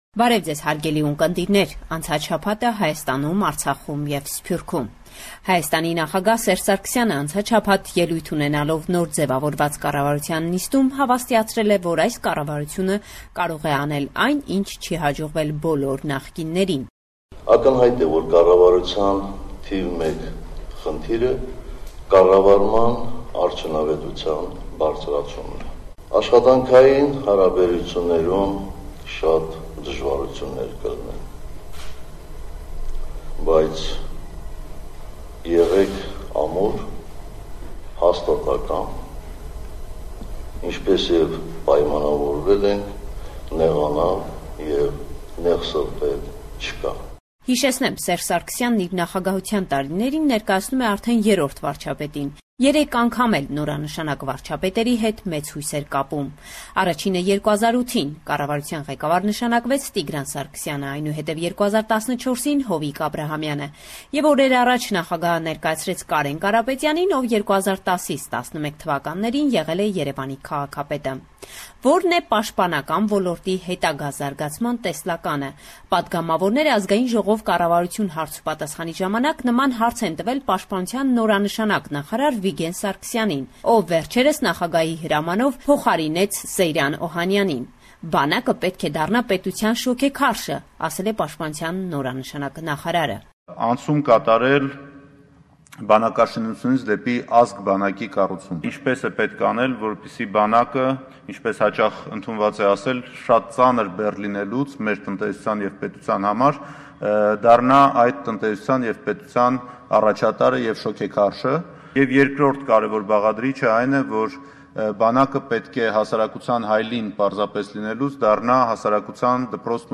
Latest News – 11 October 2016